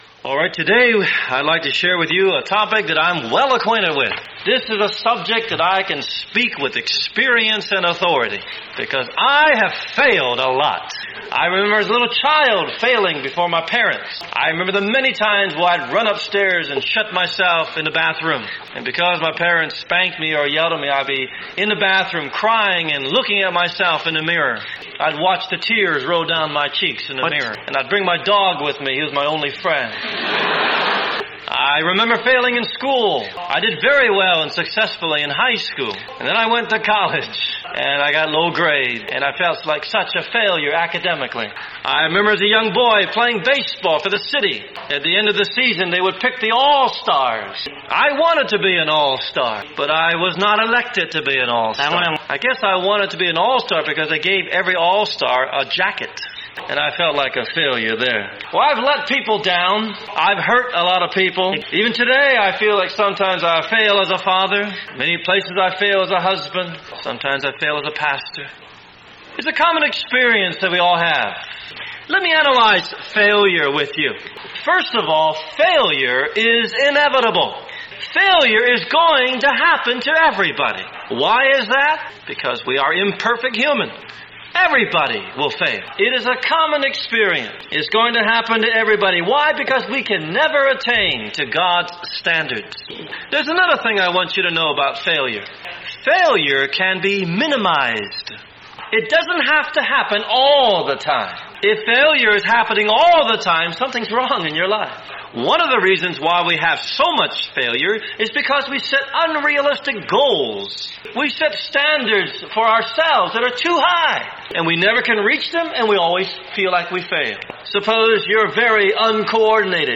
A Sermon